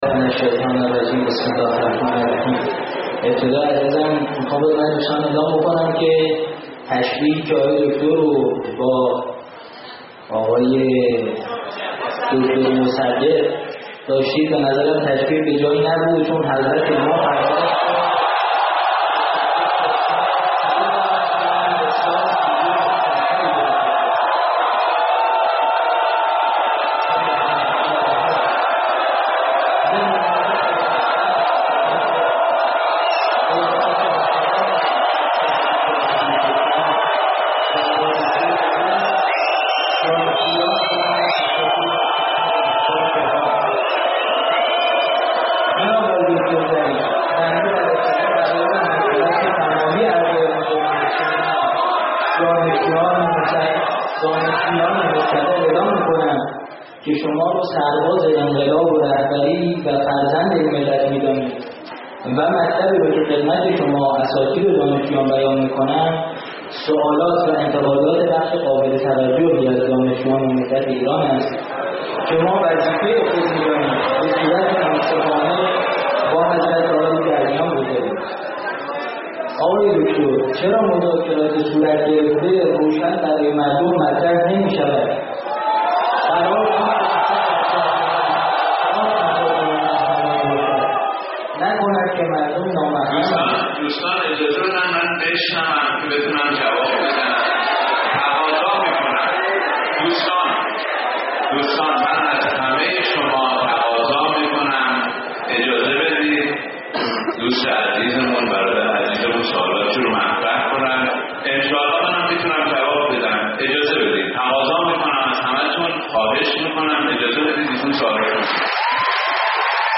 حال بنابر ادعای وزیر امور خارجه کشورمان مبنی بر تحریف سخنان وی در رسانه ها و توصیه رجوع به نوار سخنرانی ایشان در دانشگاه تهران، صوت این سخنرانی و سخنان تحریف نشده جناب ظریف در ادامه می آید.